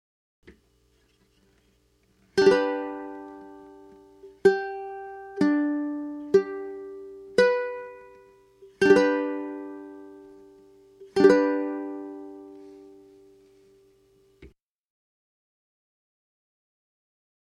Track 2 - G chord
(212K) - Finger and strum along with the G chord on your ukulele.